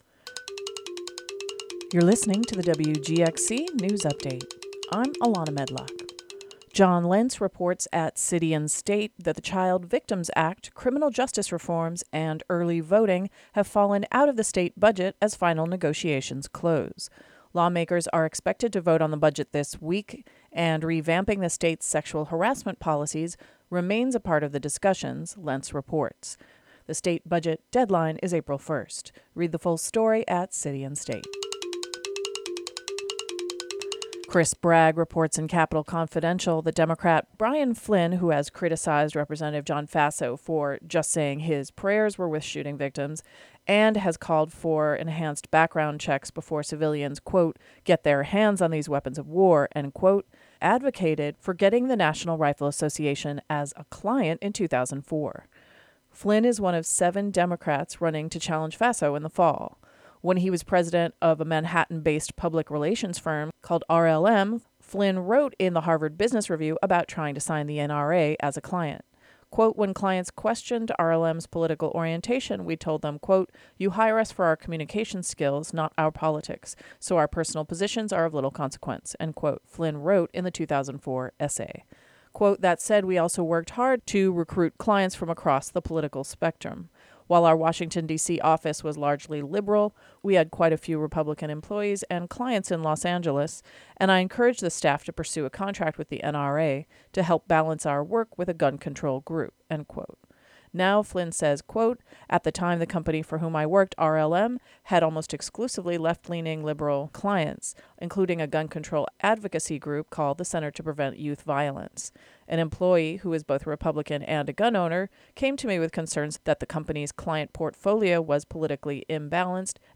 The local news update for the Hudson Valley.